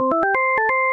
Звуки победы в игре
Звук триумфа для соревнования